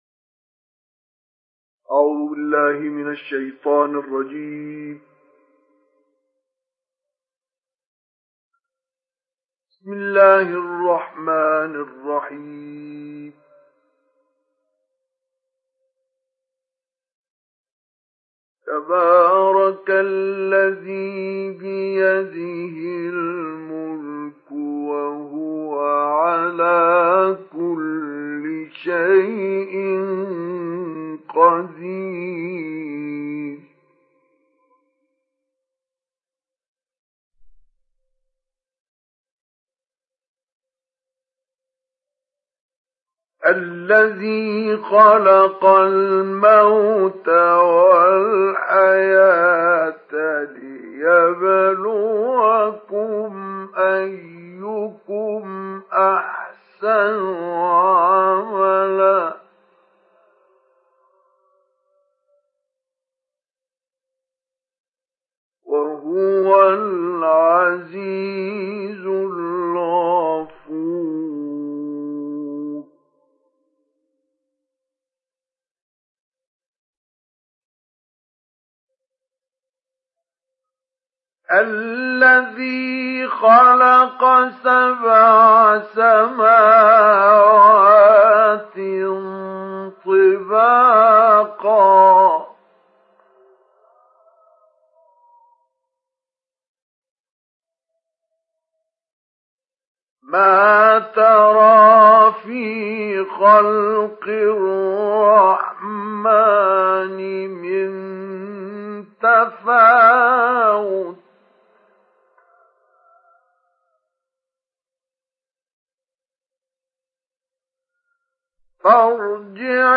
ডাউনলোড সূরা আল-মুলক Mustafa Ismail Mujawwad